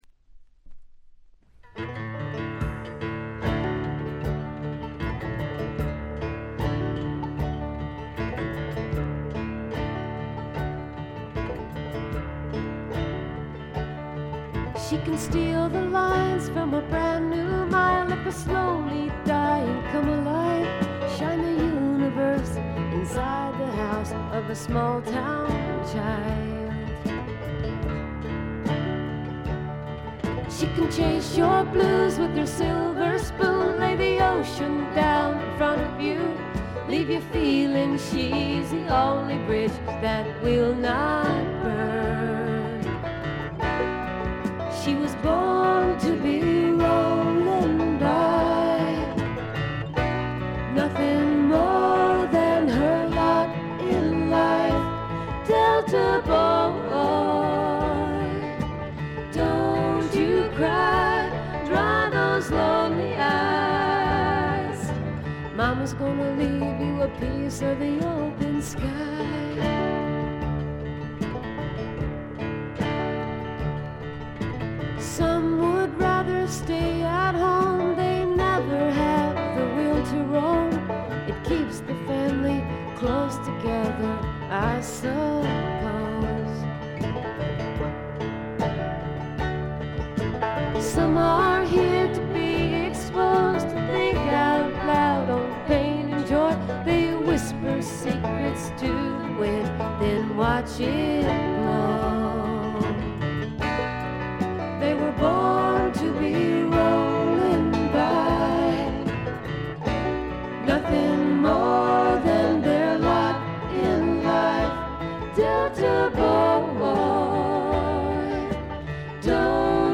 軽微なチリプチ少々。
フォーキーな女性シンガーソングライター作品の大名盤です！
試聴曲は現品からの取り込み音源です。